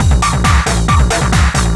DS 136-BPM A1.wav